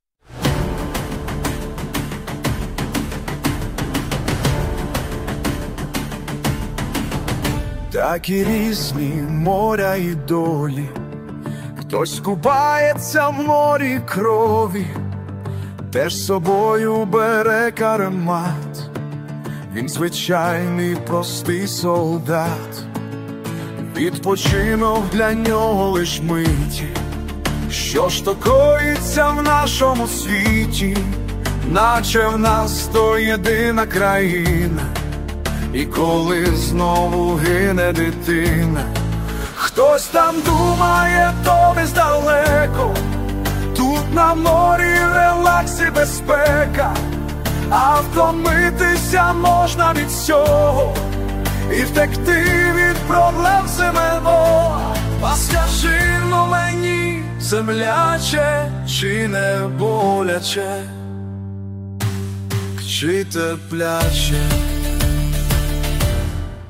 Текст - автора, пісню втілив ШІ
ТИП: Пісня
СТИЛЬОВІ ЖАНРИ: Ліричний